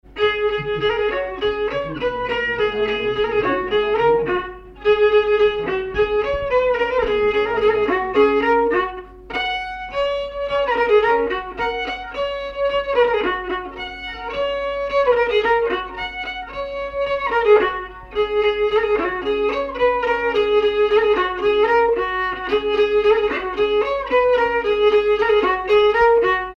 danse : polka piquée
circonstance : bal, dancerie
Pièce musicale inédite